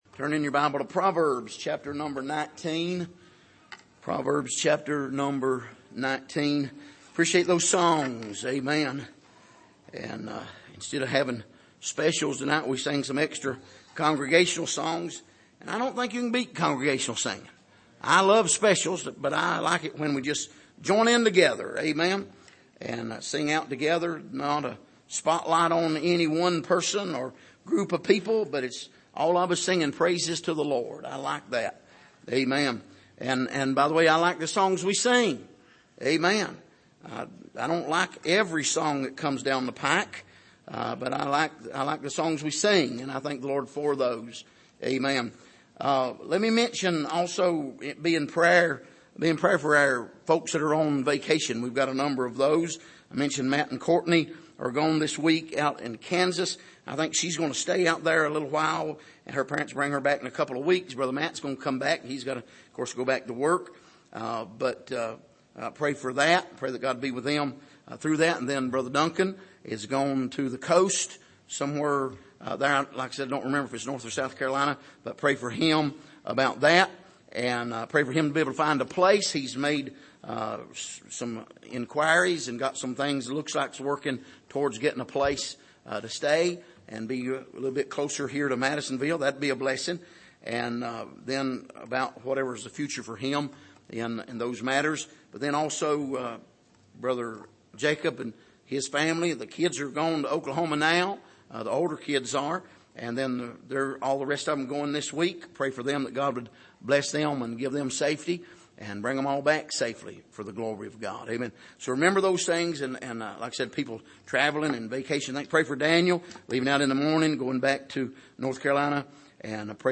Passage: Proverbs 19:8-14 Service: Sunday Evening